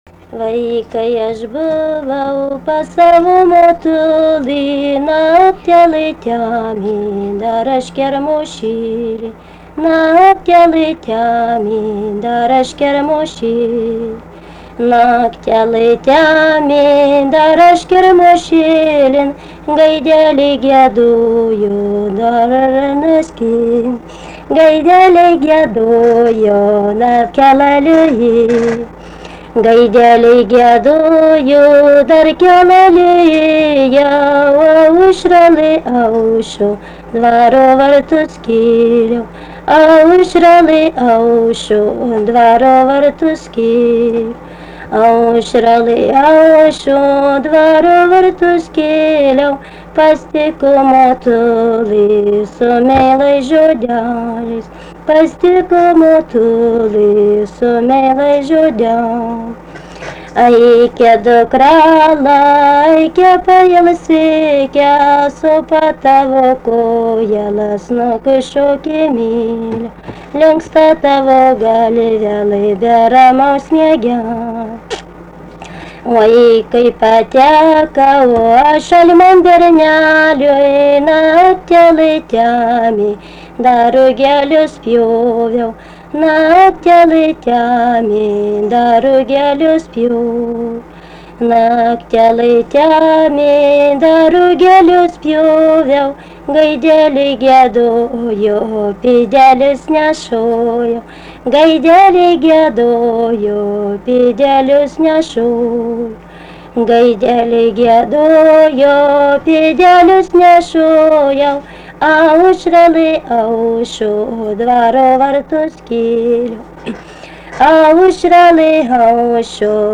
Dalykas, tema daina
Atlikimo pubūdis vokalinis
2 balsai